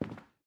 Sounds / sfx / Footsteps / Carpet / Carpet-08.wav
Carpet-08.wav